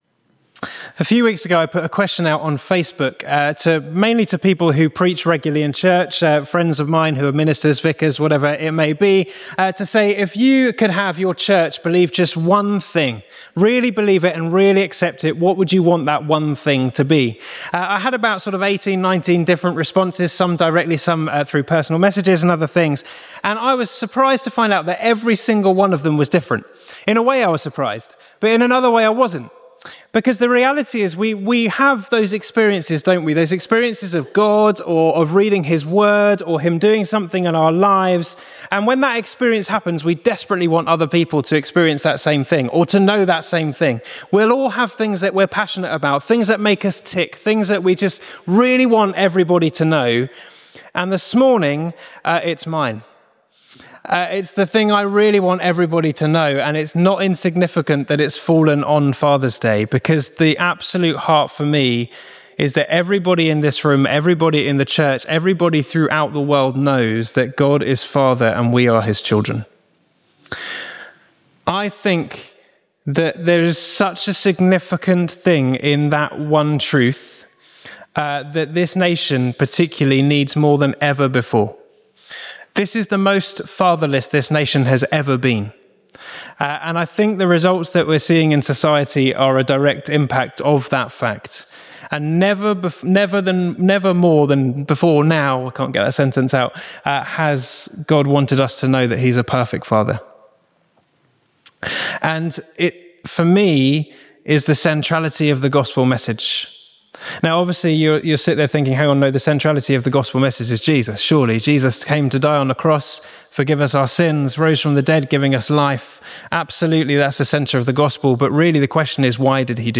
Service Type: Morning Worship Topics: Adoption , God the Father